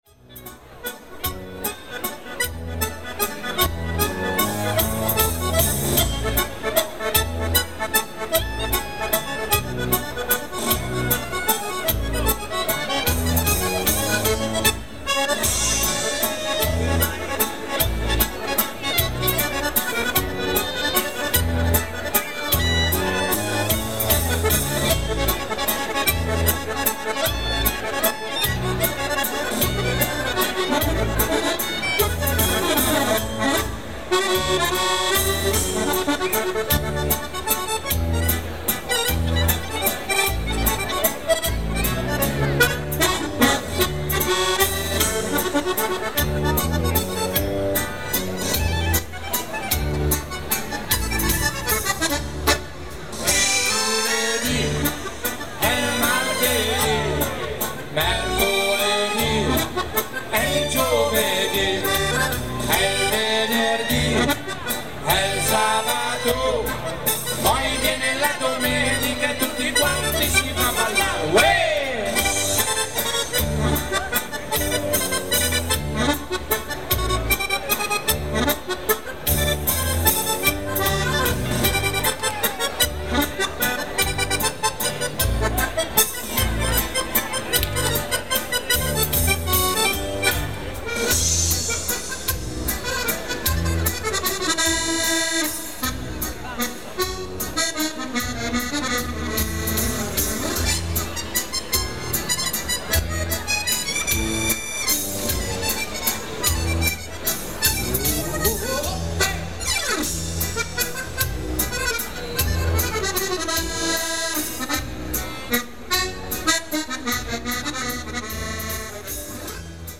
The annual San Rocco fair brings thousands of people in Barga to buy things in the markets and as evening falls they all congregate in the main square in Barga Giardino to listen to the music and to dance.